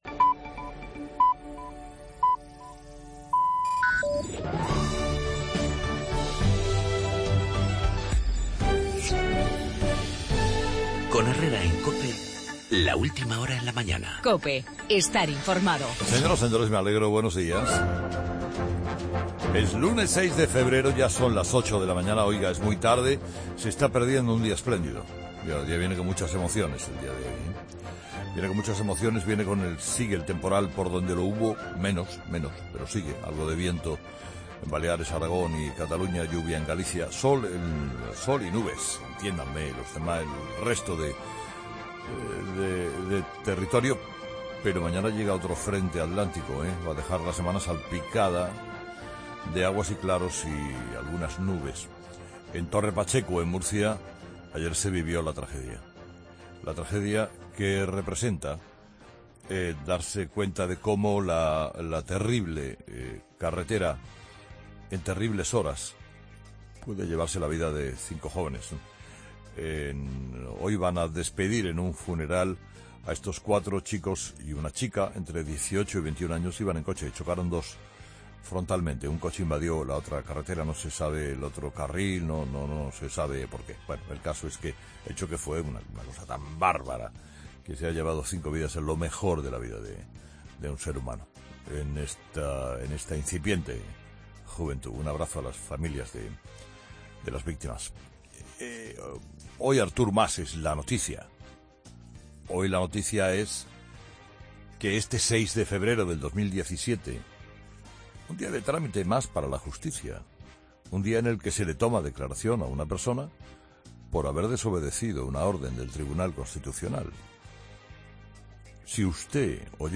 AUDIO: El juicio a Artur Mas por la desobediencia del 9N, en el monólogo de Carlos Herrera a las 8 de la mañana.